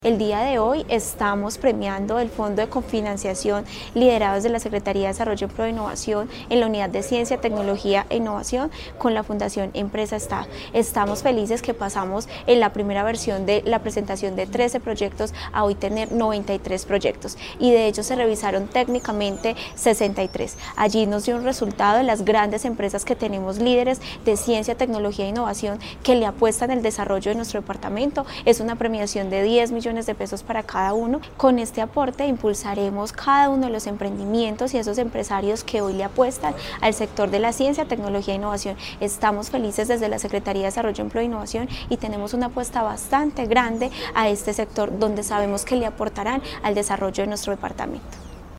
Tania Echeverry Rivera, secretaria de Desarrollo, Empleo e innovación de Caldas